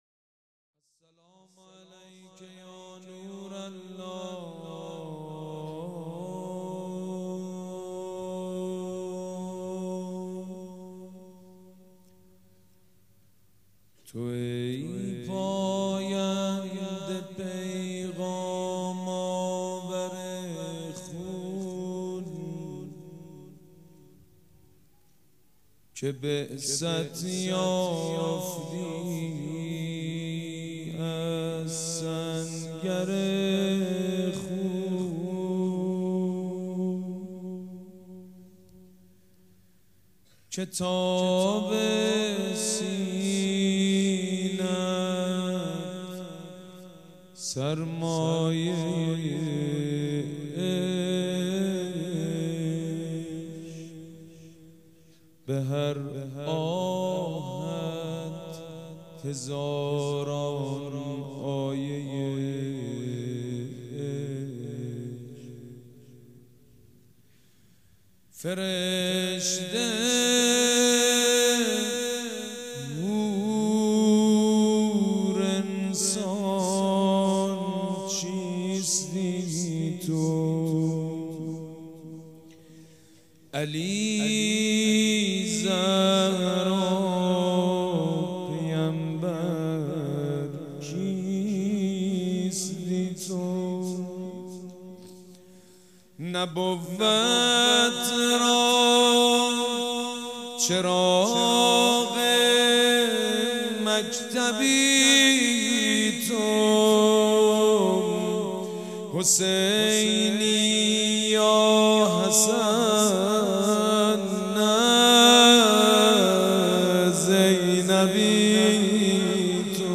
روضه بخش دوم
مراسم شب اول ماه صفر
سبک اثــر روضه